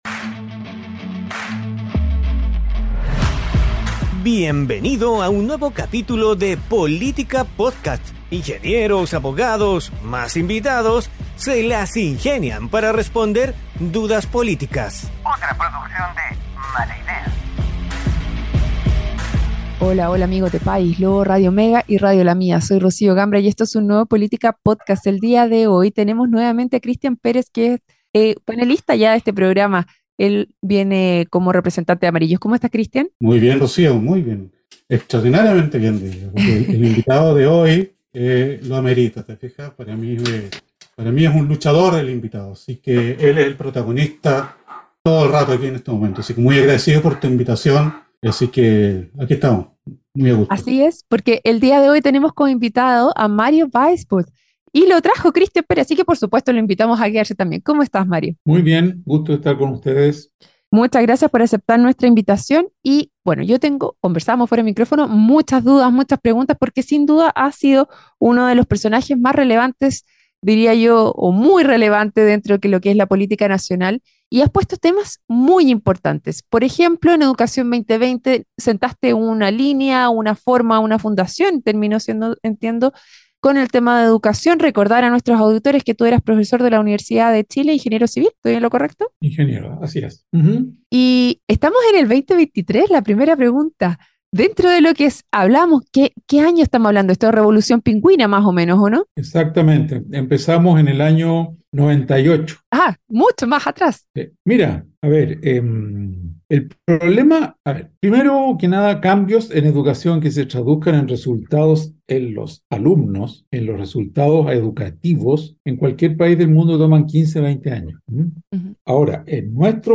junto a panelistas estables e invitados